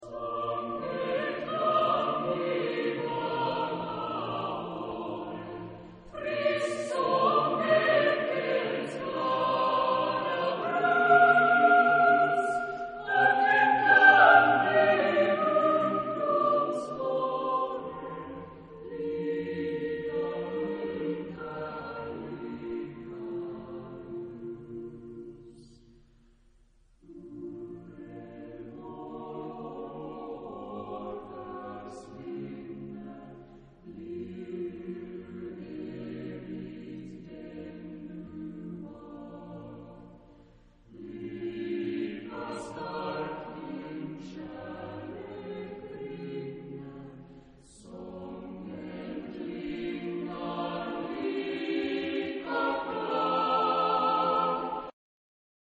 Genre-Stil-Form: weltlich
Chorgattung: SATB  (4 gemischter Chor Stimmen )